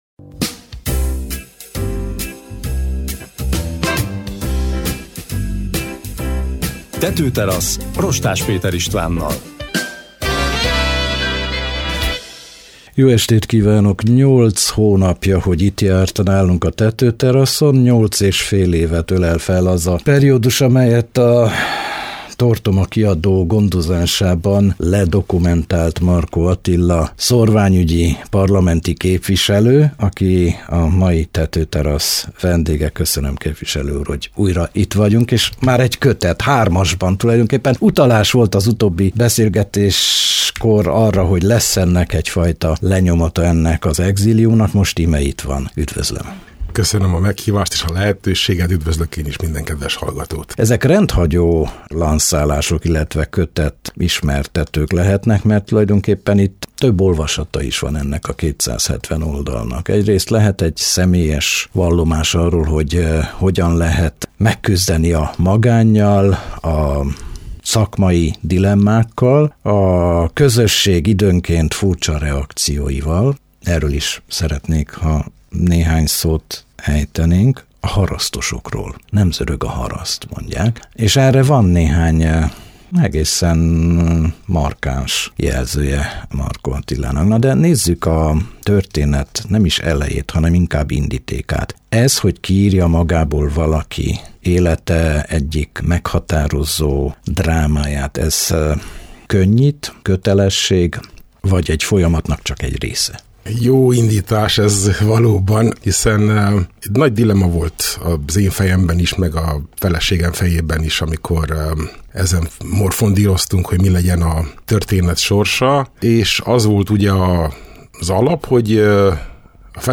Markó Attila ezt tette és hogy így járt el, emellett tanúskodik a 101 hónap – élettörténet az igazságtalanságtól az igazságig című kötet, amely a Tortoma Kiadó gondozásában jelent meg. A kötet szolgáltatott ürügyet egy tetőteraszos beszélgetésre.